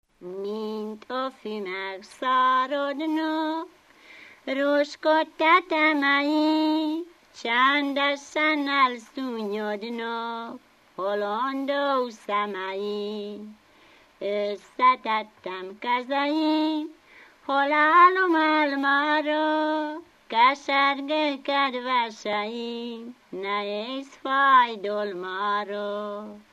Dunántúl - Somogy vm. - Szenna
ének
Stílus: 7. Régies kisambitusú dallamok
Szótagszám: 7.6.7.6
Kadencia: 5 (5) 2 1